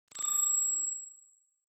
دانلود آهنگ هشدار 1 از افکت صوتی اشیاء
دانلود صدای هشدار 1 از ساعد نیوز با لینک مستقیم و کیفیت بالا
جلوه های صوتی